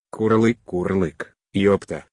да про бота я, этот голос Ivona а там вроде лимит бесплатных запросов